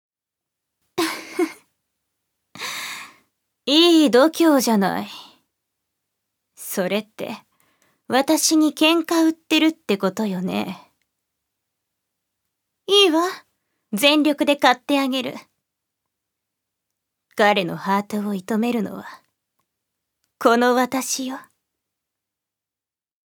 ジュニア：女性
セリフ３